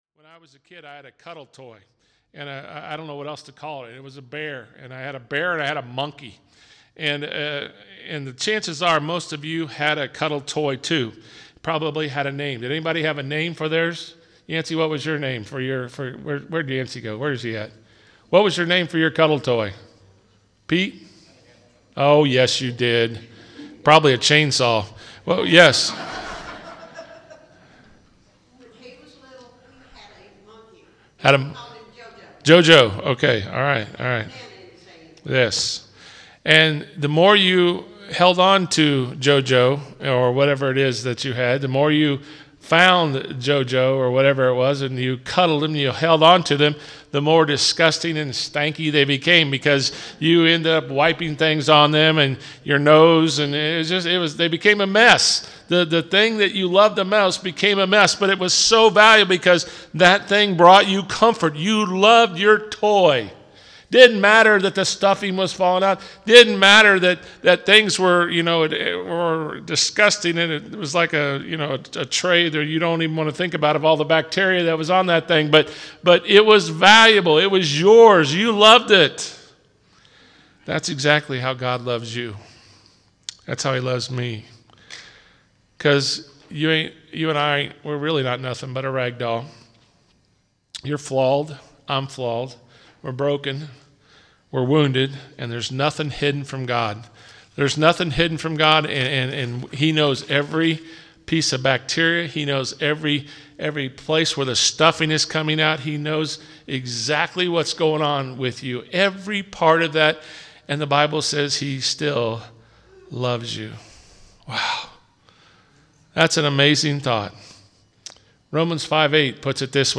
Sermon-2-20-22.mp3